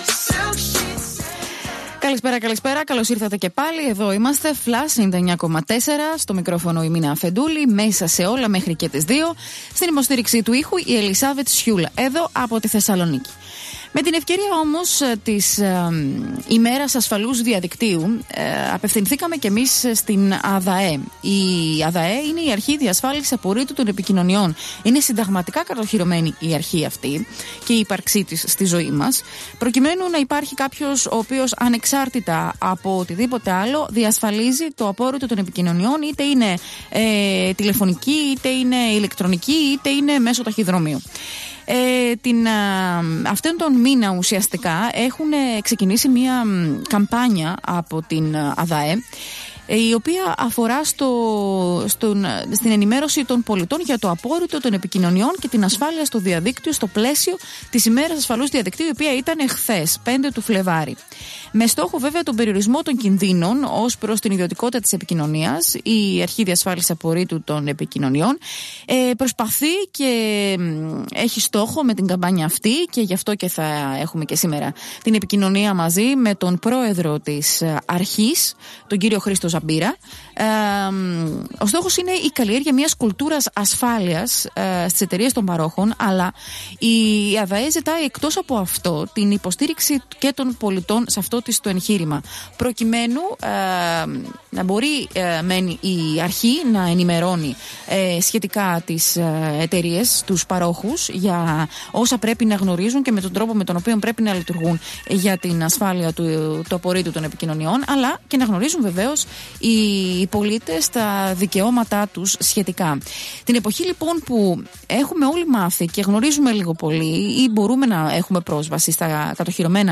Συνέντευξη του Προέδρου της ΑΔΑΕ κ. Χρήστου Ζαμπίρα
Μπορείτε να ακούσετε τη ραδιοφωνική συνέντευξη από τον ακόλουθο σύνδεσμο του Ραδιοφωνικού Σταθμού:ΣΥΝΕΝΤΕΥΞΗ ΠΡΟΕΔΡΟΥ ΤΗΣ ΑΔΑΕ ΧΡΗΣΤΟΥ ΖΑΜΠΙΡΑ στον FLASH RADIO 99,4